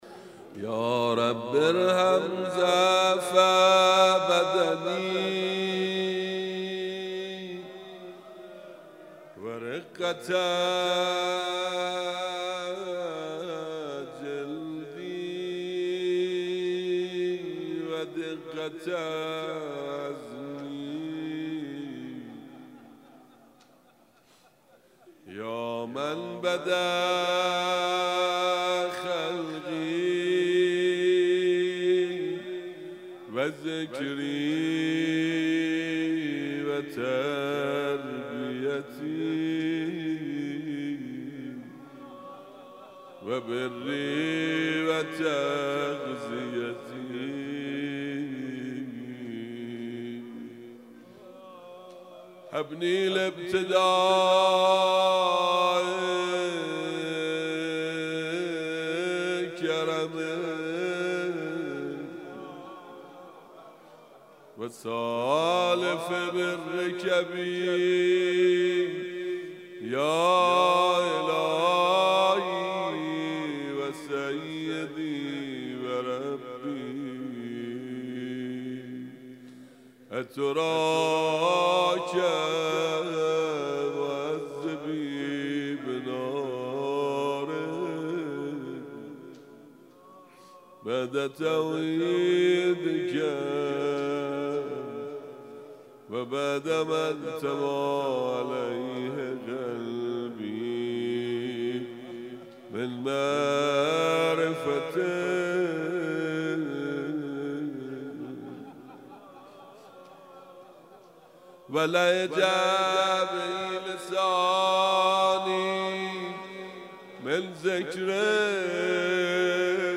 مسجد ارک